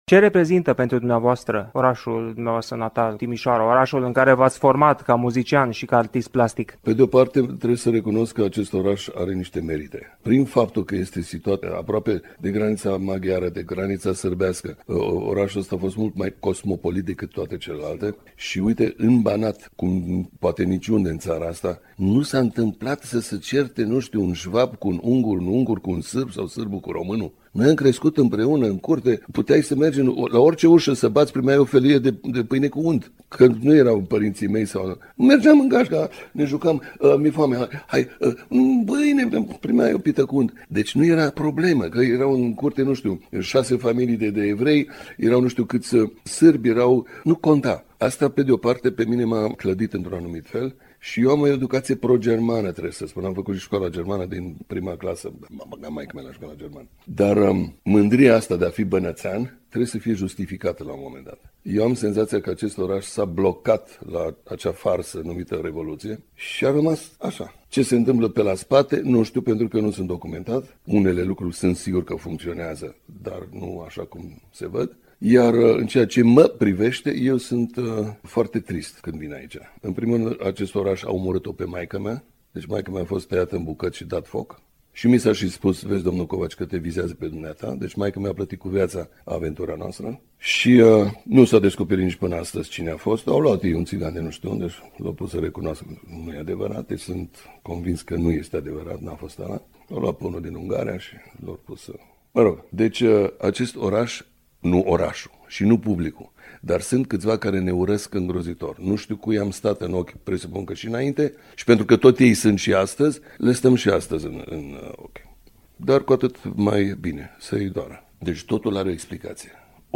Motto-ul vieții. „Metoda” preferată de a compune. Fragmente de interviu